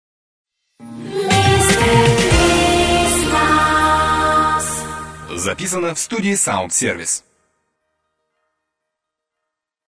Джингл